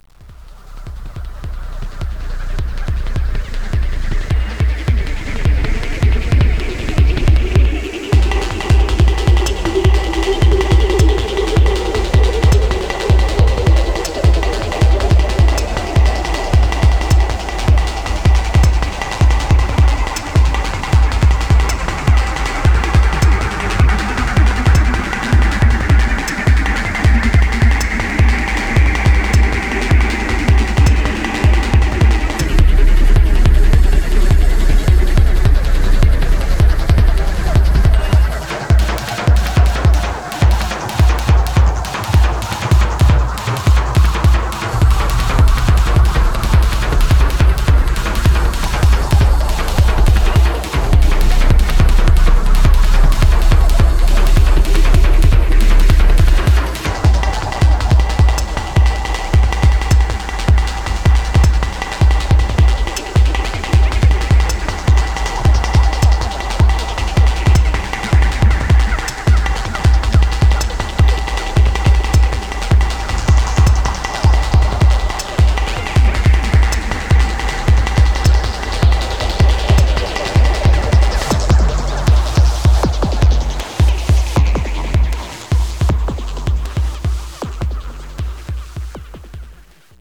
ハーフタイム、ポリリズムが錯綜、自動生成されてゆくような、テクノの何か得体の知れない領域が拡大しています。